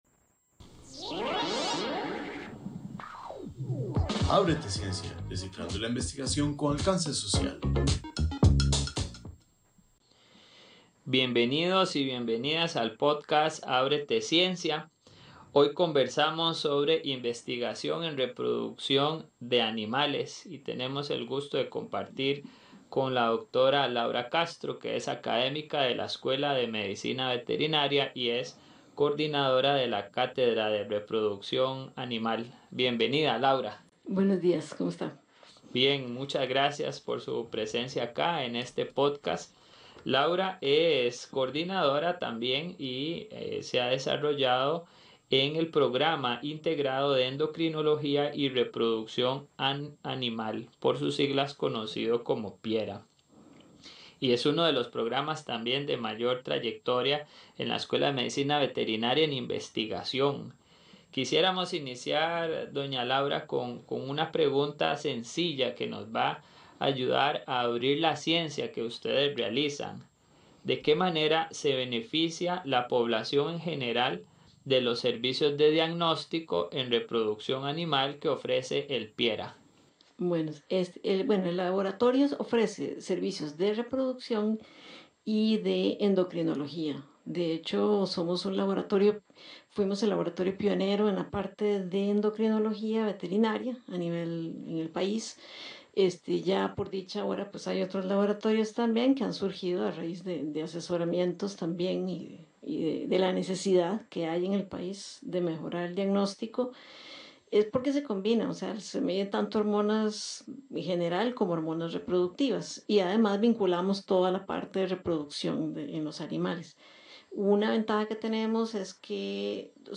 ¡Bienvenidos al podcast ábrete ciencia!